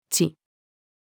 智-female.mp3